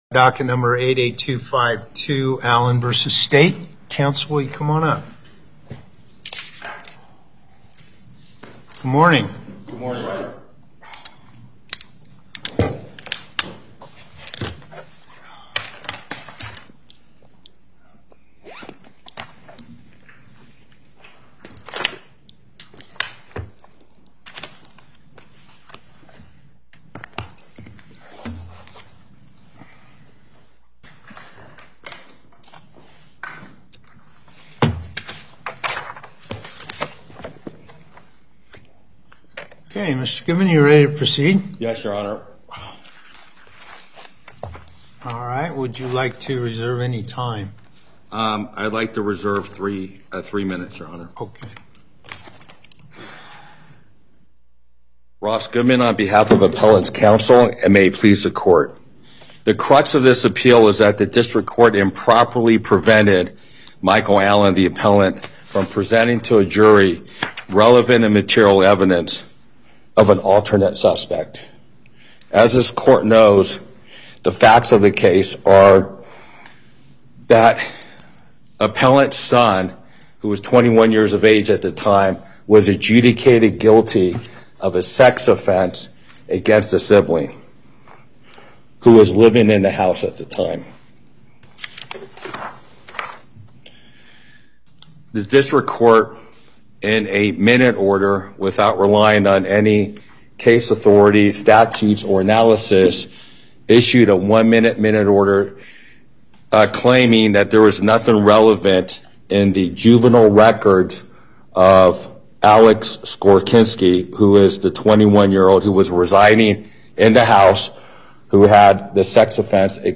Before Panel A25, Justice Parraguirre presiding Appearances